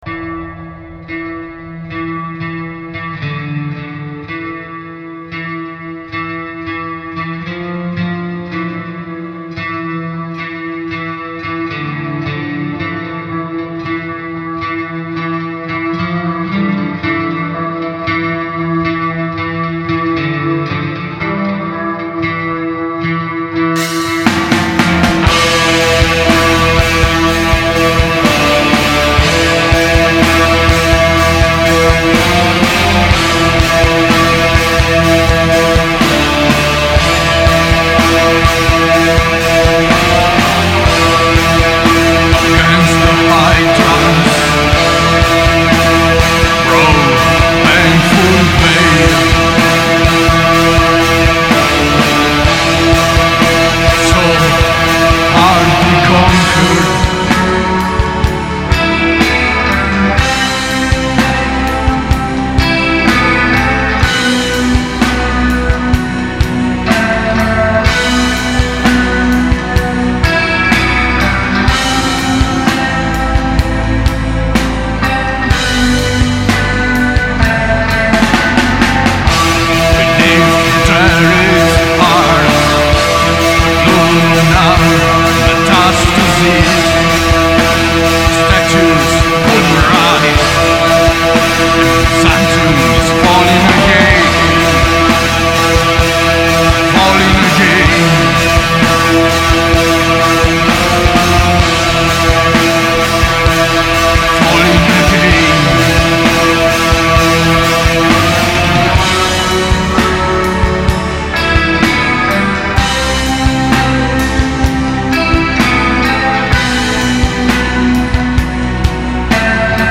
Dark wave